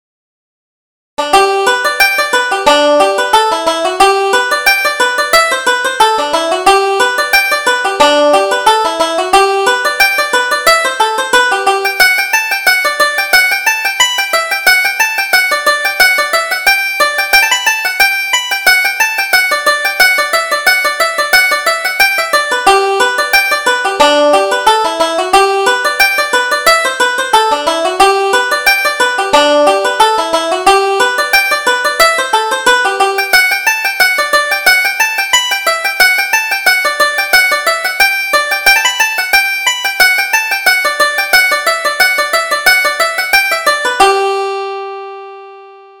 Reel: Kiss Me Kate